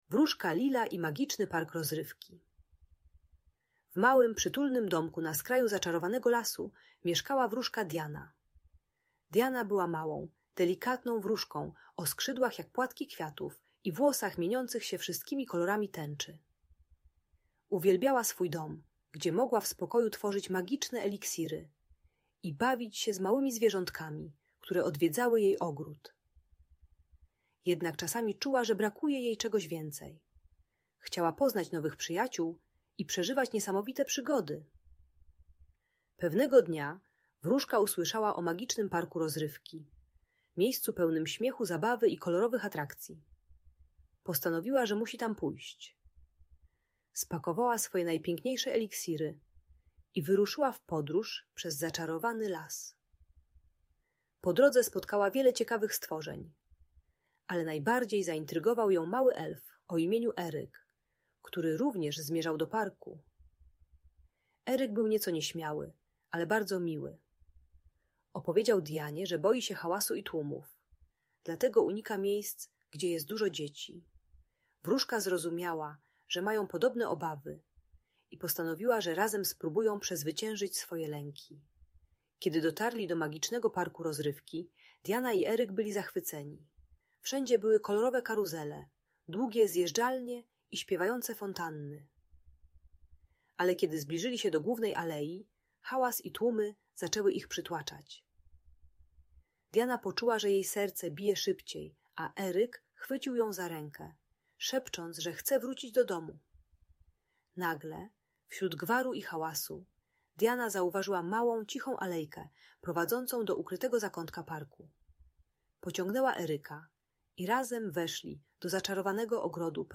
Magiczna historia wróżki Diany i Magicznego Parku Rozrywki - Audiobajka dla dzieci